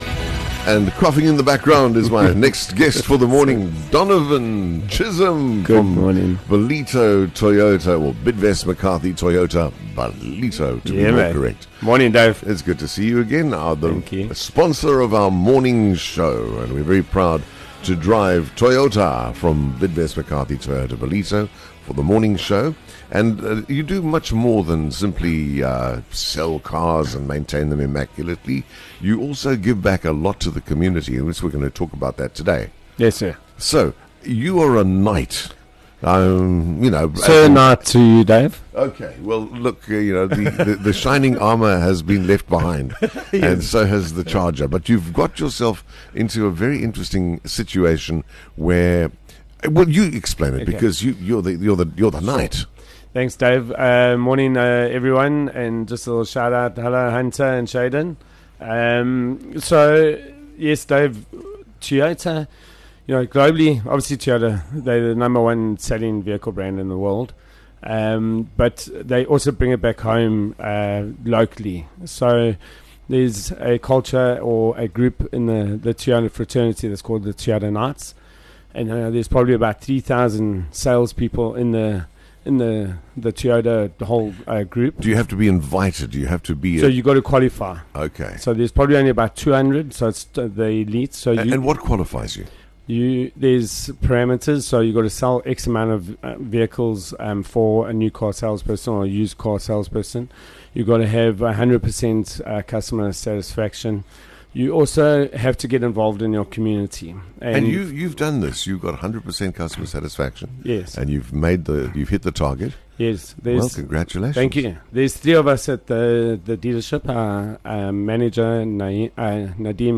Join us for a featured chat with our valued sponsors of the Morning Show – Bidvest McCarthy Toyota Ballito, driving excellence every morning.